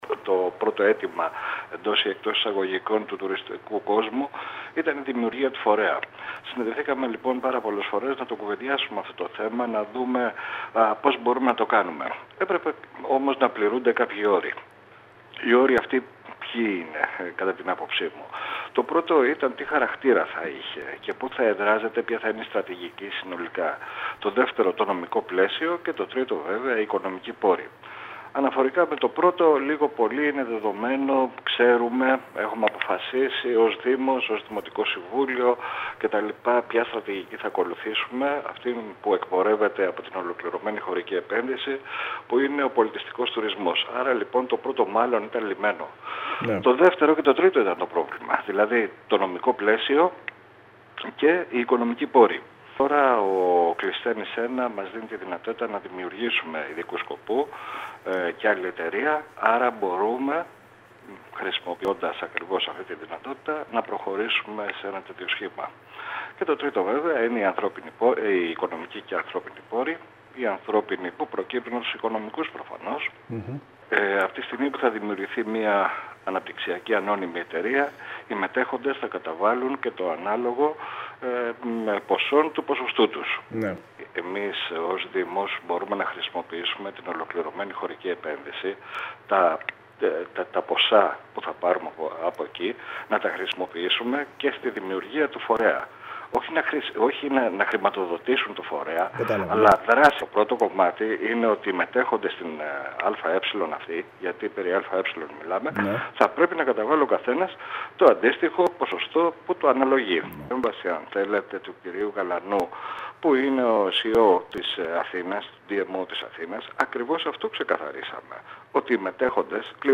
Αναλύοντας την πρόταση αυτή στην ΕΡΤ Κέρκυρας, ο Αντιδήμαρχος Τουρισμού Βασίλης Καββαδίας, ανέφερε ότι ο οργανισμός θα έχει τη μορφή Ανώνυμης Επιχείρησης, την πλειοψηφία – όπως προβλέπει ο νομοθέτης – θα έχει ο Δήμος αλλά στη σύνθεσή της θα μπορεί να συμμετάσχει κάθε πολιτικός, θεσμικός ή επαγγελματικός φορέας του νησιού.